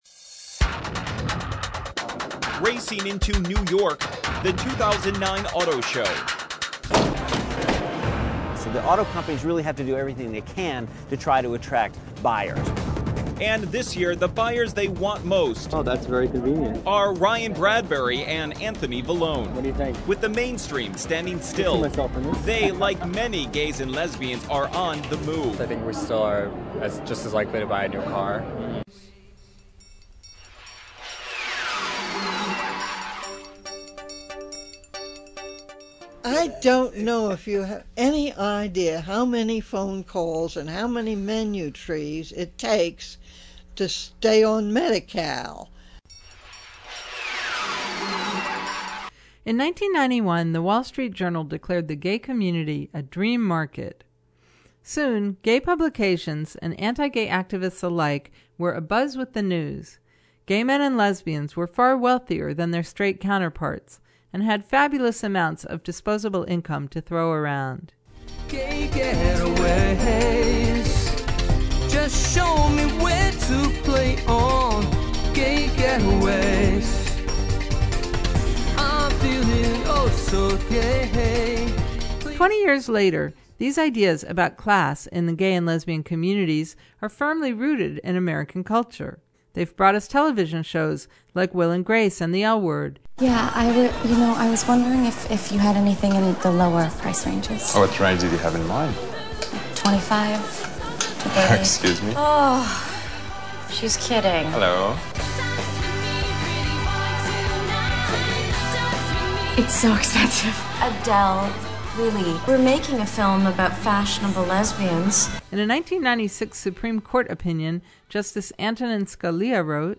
The Queerness of Class is a 28-minute radio documentary on the perceptions vs realities of class and income in the gay and lesbian community. Where did the idea that gay people are more affluent than straight people come from, and is it true?